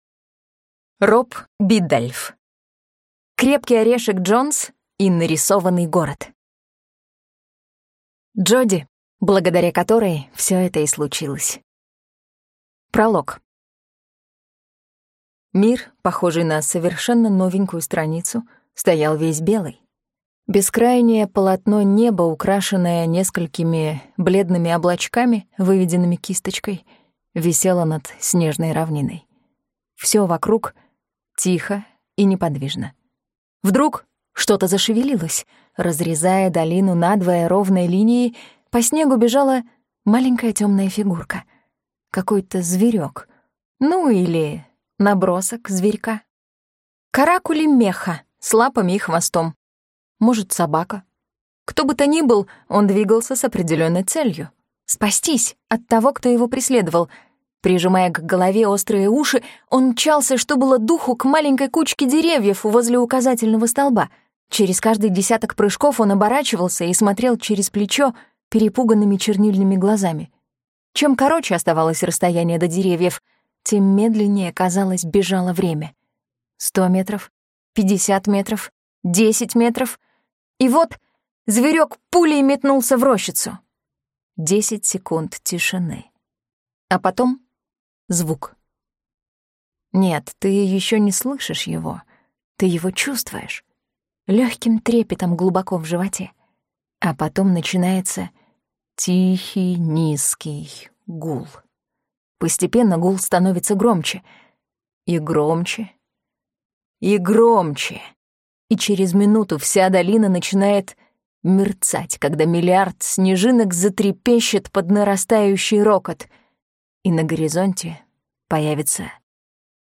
Аудиокнига Крепкий орешек Джонс и нарисованный город | Библиотека аудиокниг